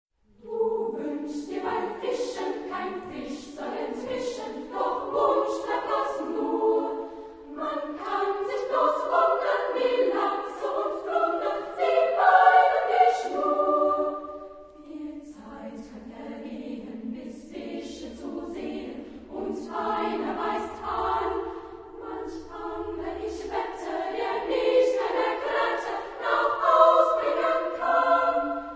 Género/Estilo/Forma: Profano ; Lied
Tipo de formación coral: SSA  (3 voces Coro infantil O Coro femenino )
Tonalidad : re mayor
Ref. discográfica: 5.Deutscher Chorwettbewerb, 1998